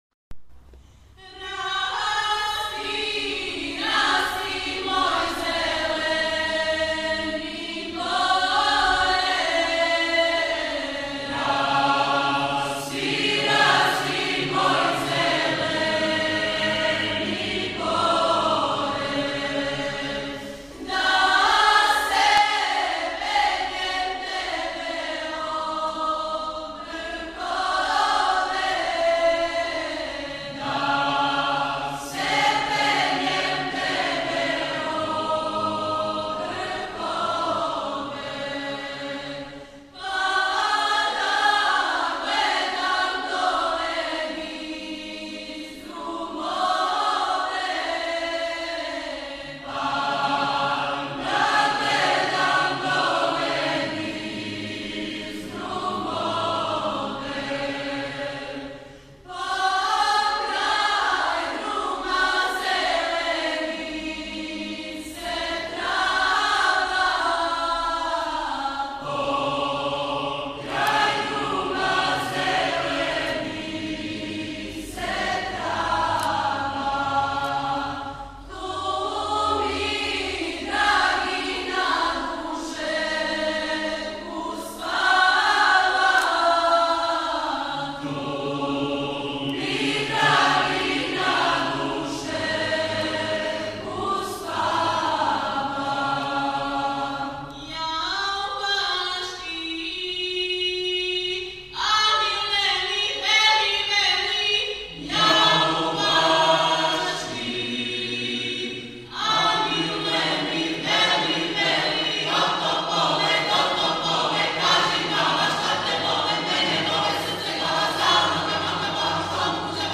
песме са Косова, сплет руских и украјинских народних песама
Део тонског записа концерта можете преузети ОВДЕ .